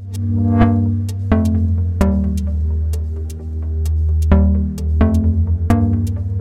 Category: Message Ringtones